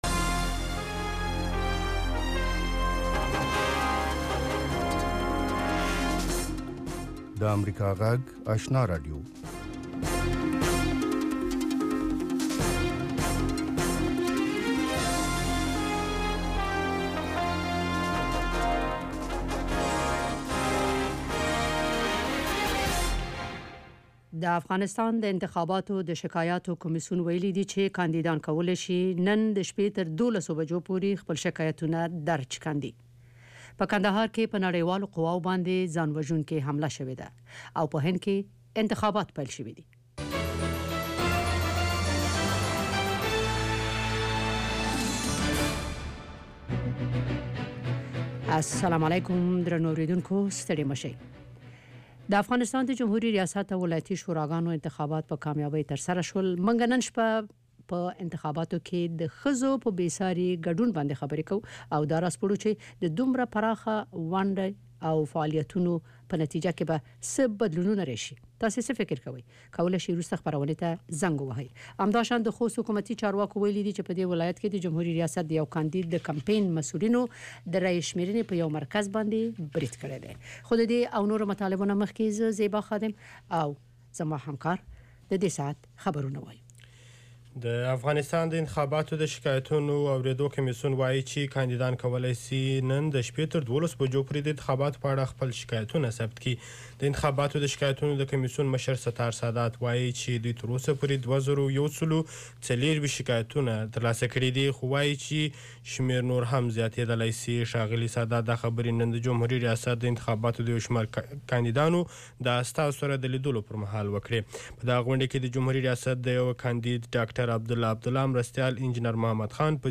یو ساعته خپرونه: تازه خبرونه، د ځوانانو، میرمنو، روغتیا، ستاسو غږ، ساینس او ټیکنالوژي، سندرو او ادب په هکله اونیز پروگرامونه.